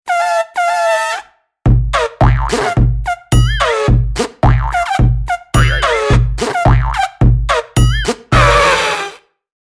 01Elefant.wav